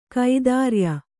♪ kaidārya